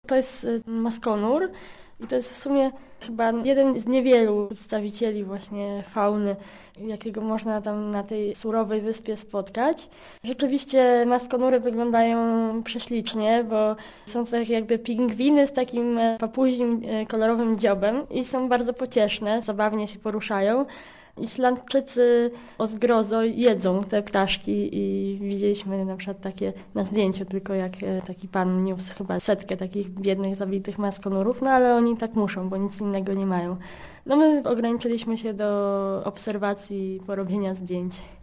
Numer 2/2003 zawiera 10 relacji polskich podróżników z różnych krajów świata: 80 ekranów tekstowych z praktycznymi informacjami i reportażami, 250 minut radiowych opowieści i muzyki etnicznej ilustrowanych slajdami, 1530 podpisa- nych zdjęć oraz 40 minut sekwencji filmowych.